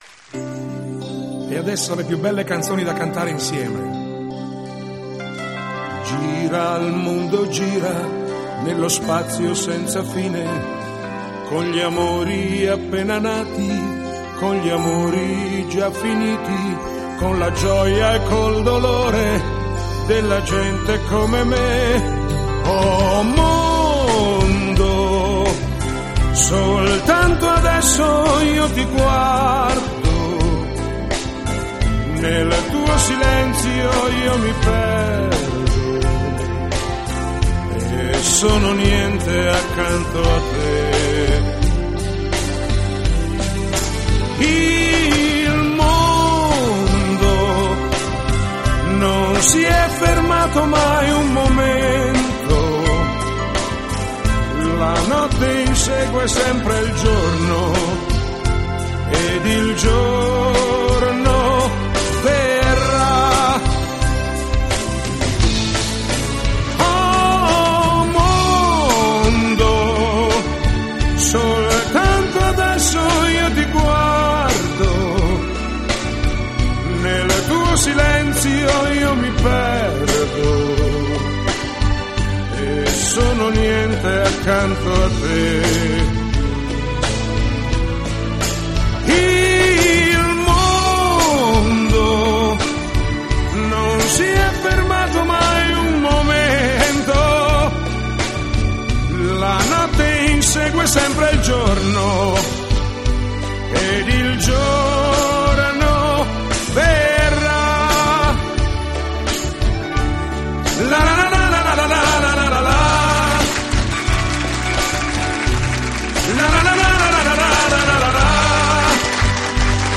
Video Karaoke) + Cori